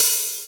HAT DANCE 1P.wav